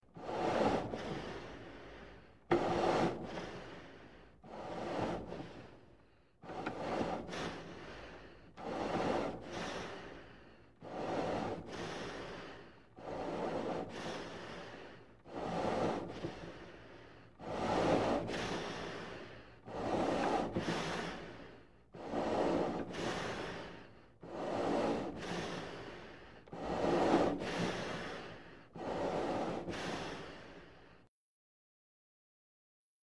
Шум дыхания космонавта в скафандре